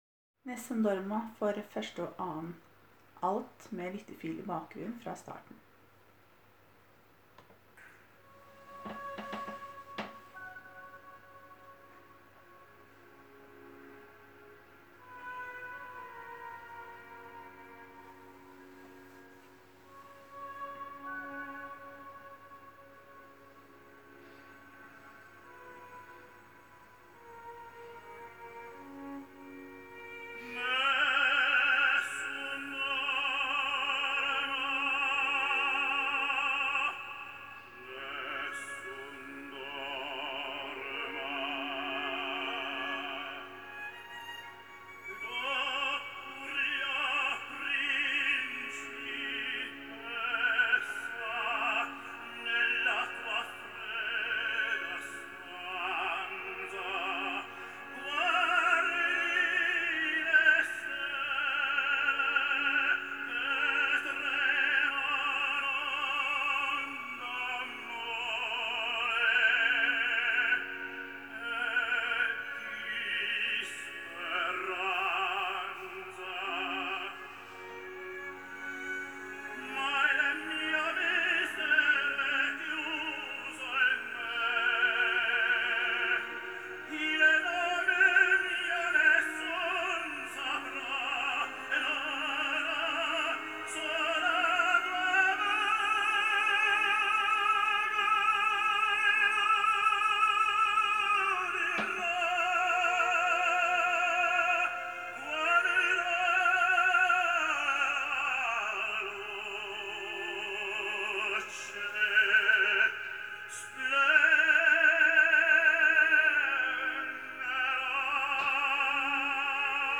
Haldens største blanda kor!
Nessun dorma med lyttefil  i bakgrunnen: